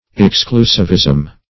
Search Result for " exclusivism" : The Collaborative International Dictionary of English v.0.48: Exclusivism \Ex*clu"siv*ism\, n. The act or practice of excluding being exclusive; exclusiveness.